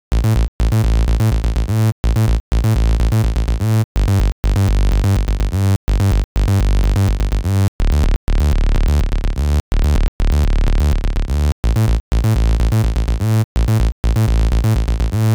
sawbass125bpm.ogg